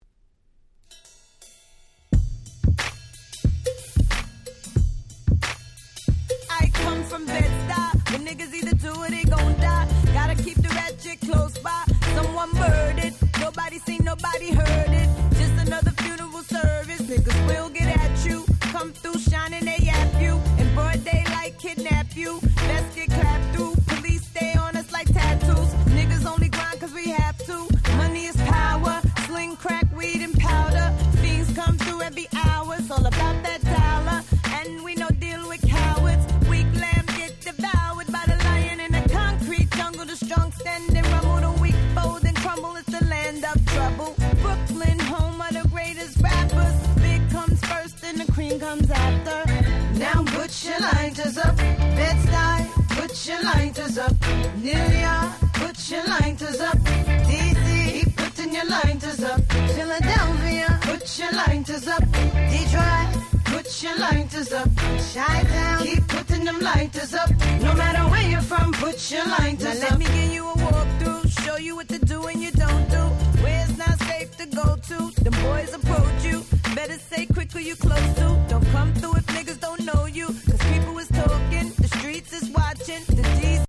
05' Big Hit Hip Hop !!